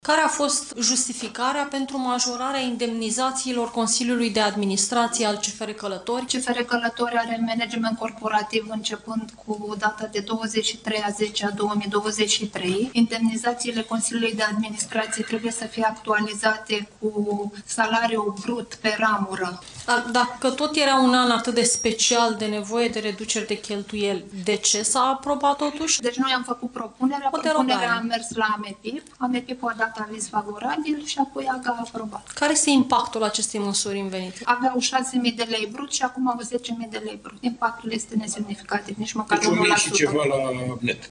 Indemnizațiile Consiliului de Administrație al CFR Călători au fost majorate, deși Guvernul a cerut măsuri de reducere a cheltuielilor. Într-o conferință de presă, conducerea CFR Călătoria explicat de ce a fost necesară această creștere.